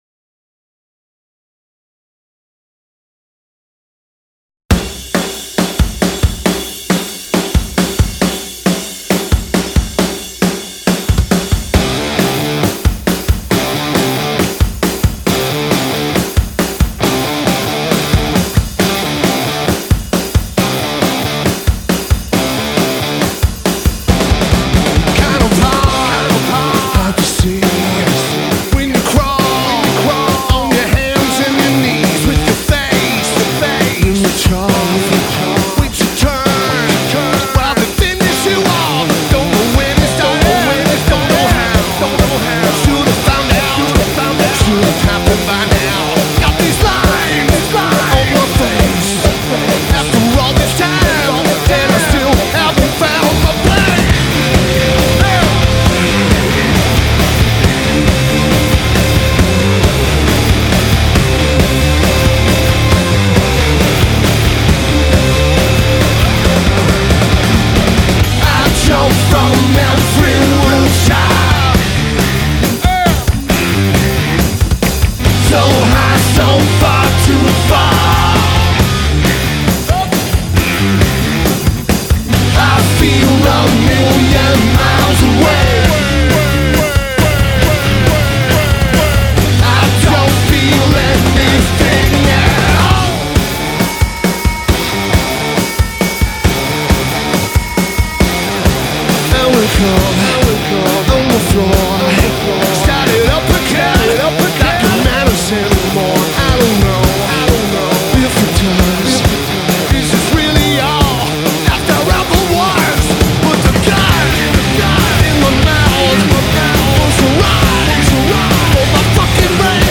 (Live at rehearsal)